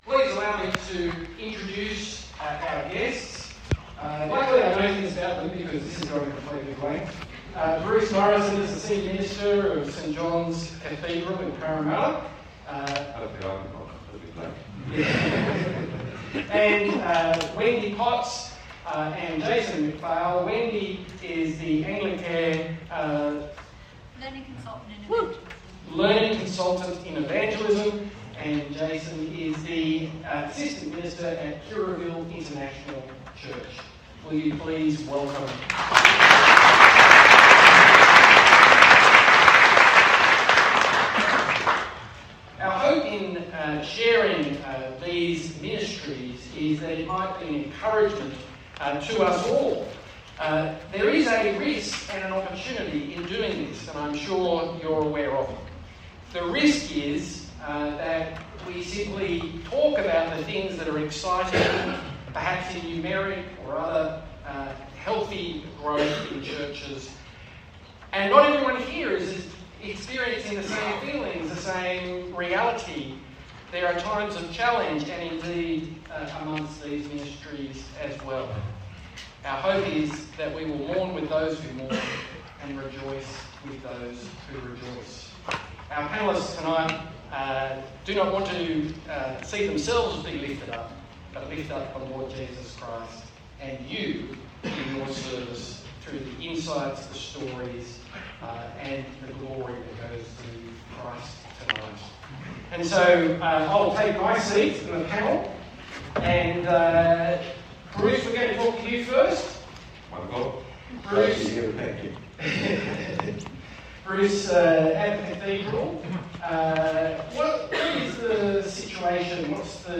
If you missed the panel discussion at the ACL Synod Dinner on 15ht September, with the topic of Hope from the Coal Face, the audio recording is available here: